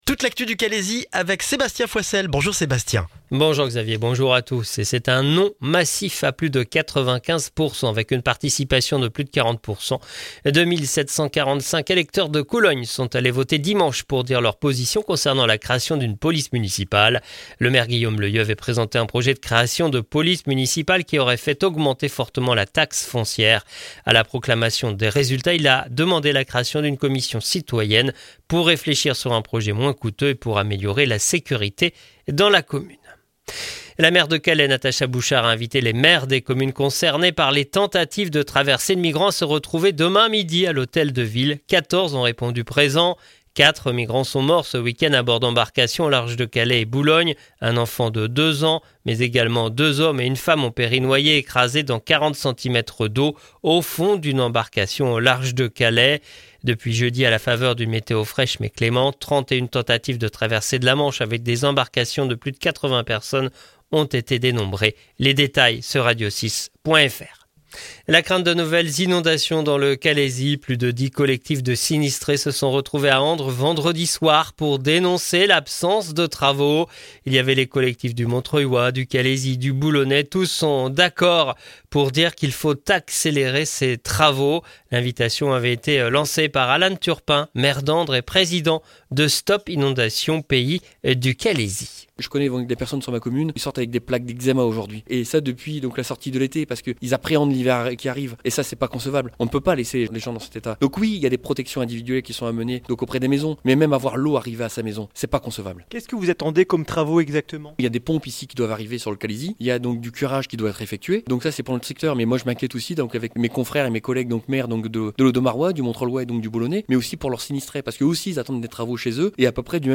Le journal du lundi 7 octobre dans le Calaisis